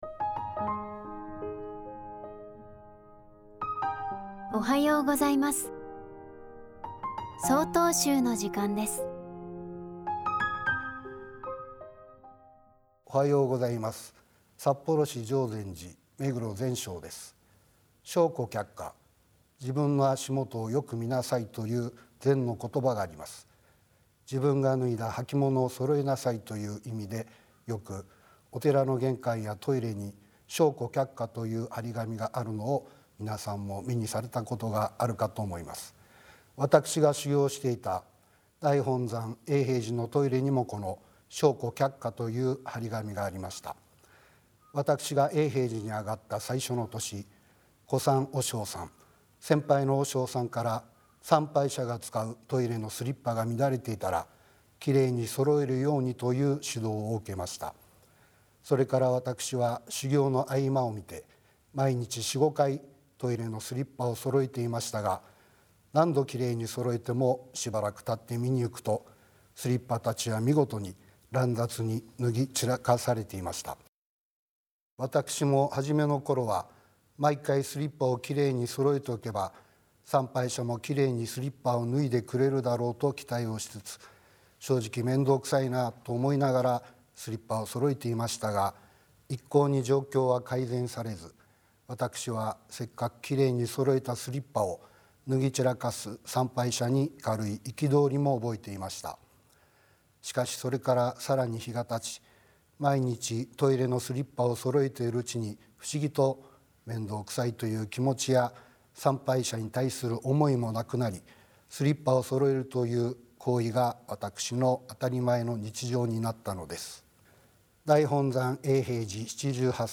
法話